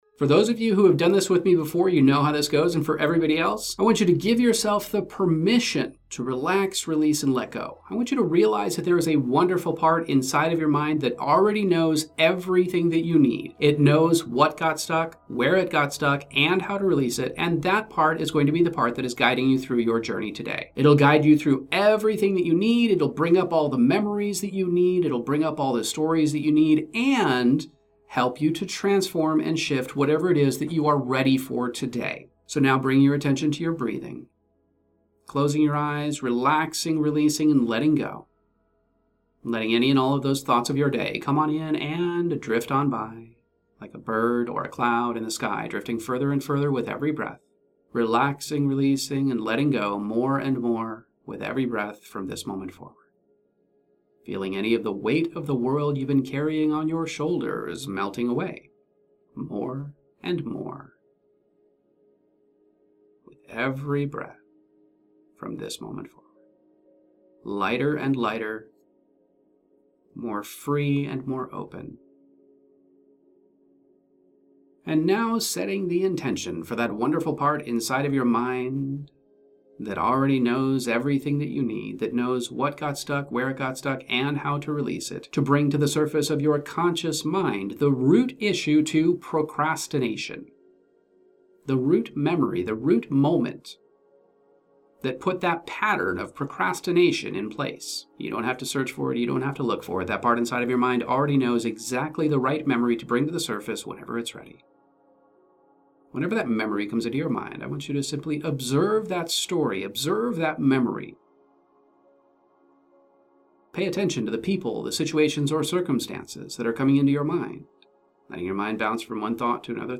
This guided hypnosis meditation is basically like a secret weapon for anyone who's ever felt stuck or like they're always putting things off. It's all about getting you to chill out first, then diving deep into your brain to figure out why you're procrastinating.